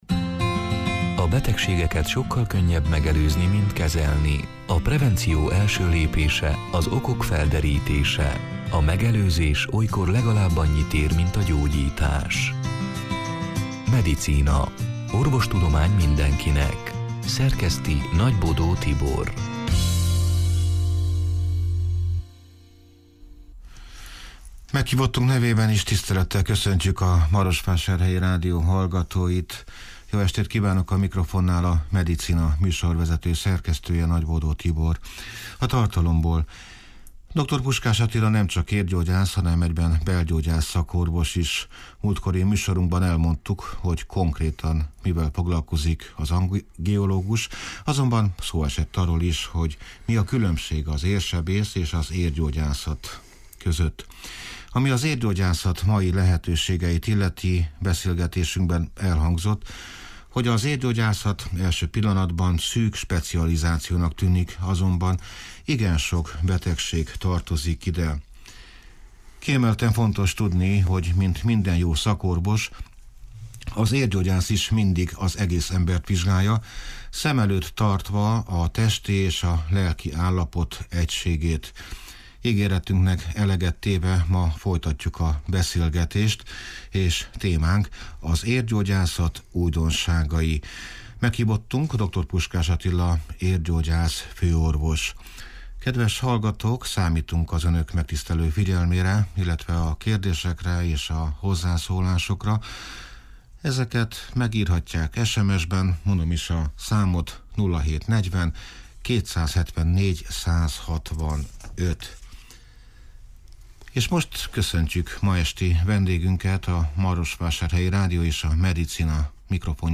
(elhangzott: 2022. október 5-én, szerdán este nyolc órától élőben)